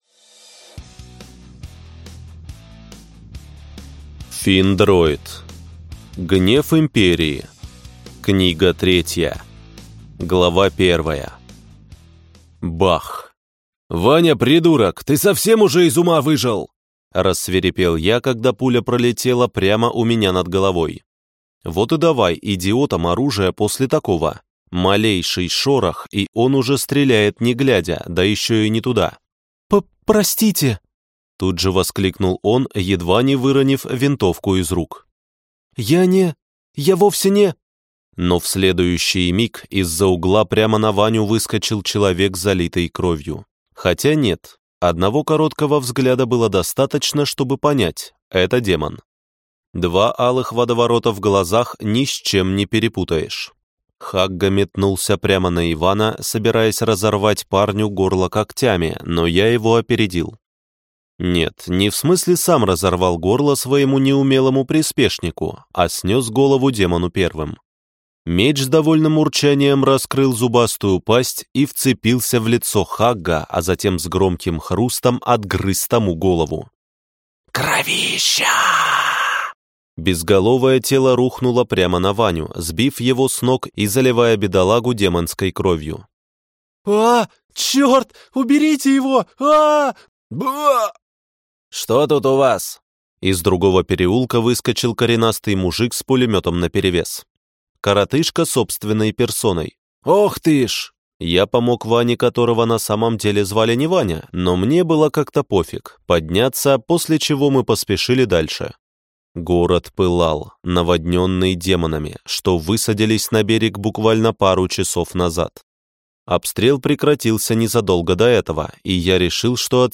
Аудиокнига Гнев Империи. Книга 3 | Библиотека аудиокниг